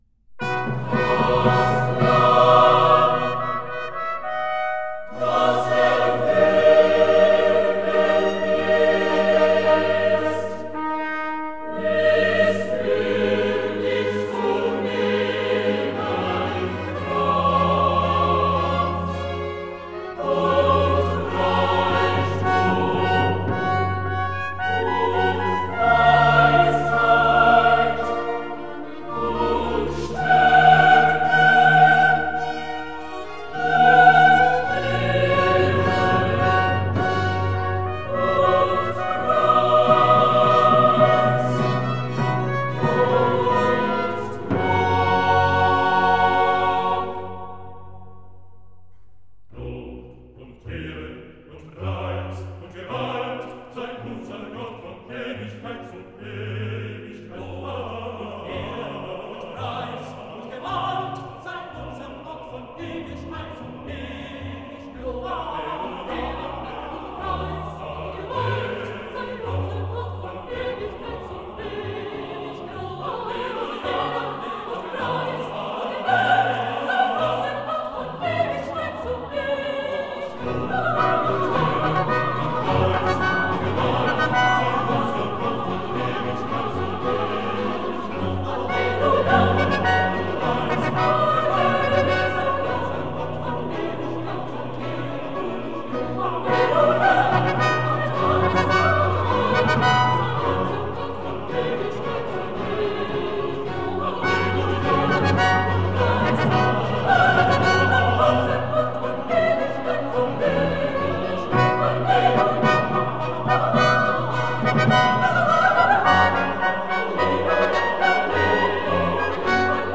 Das Lamm, das erwürget ist (Chorus)